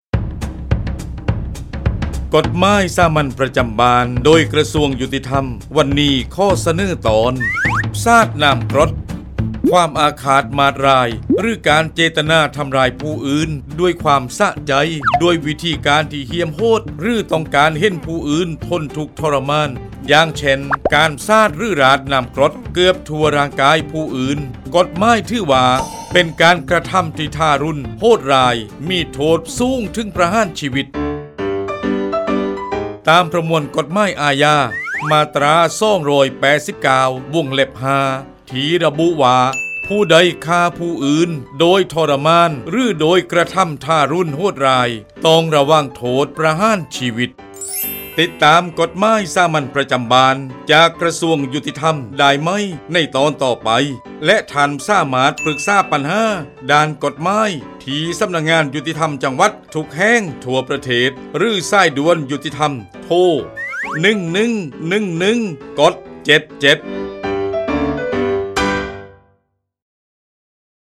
กฎหมายสามัญประจำบ้าน ฉบับภาษาท้องถิ่น ภาคใต้ ตอนสาดน้ำกรด
ลักษณะของสื่อ :   บรรยาย, คลิปเสียง